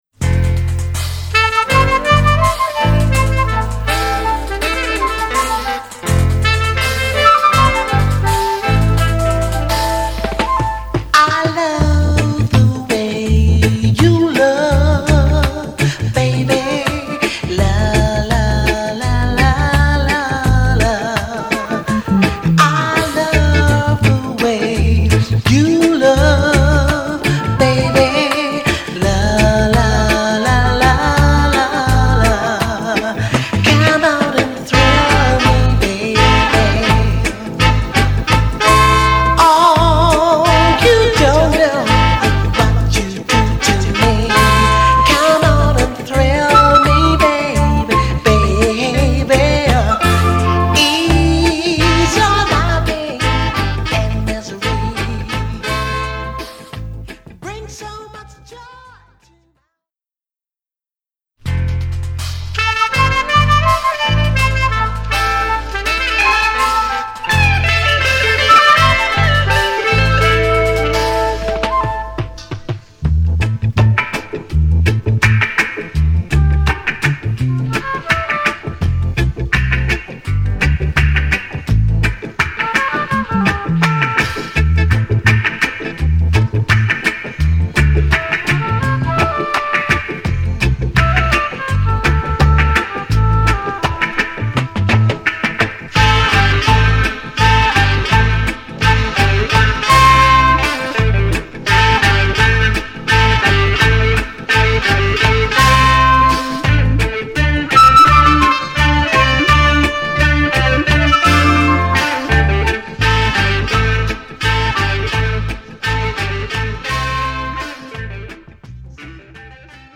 7inch